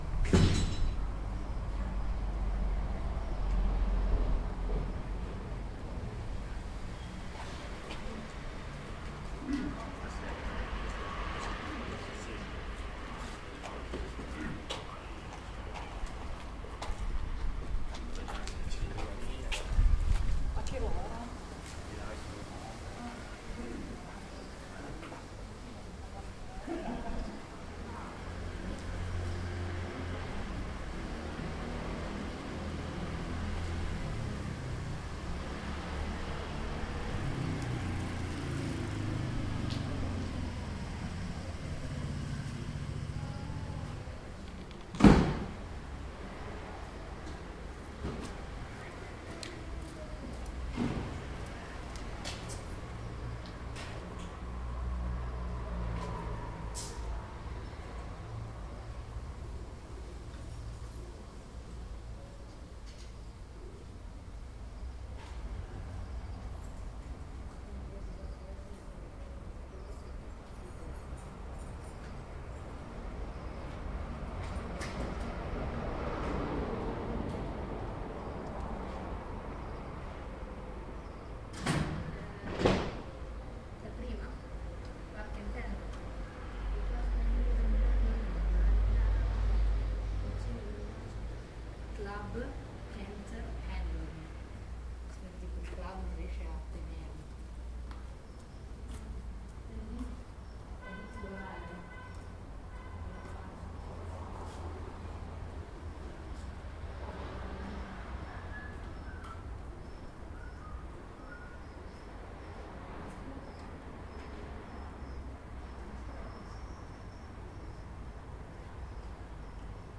Tag: 喋喋不休 音景 交通 噪音 博洛尼亚 现场录音 户外